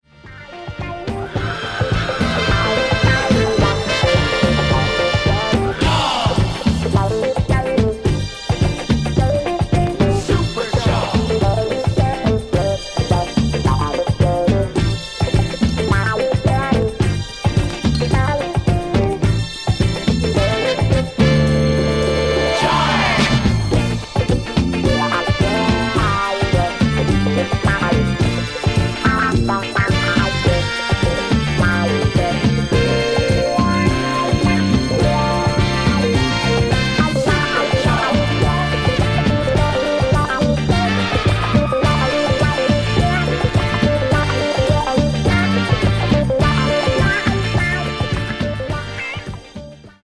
Genere:   Disco | Funk | Sunshine Sound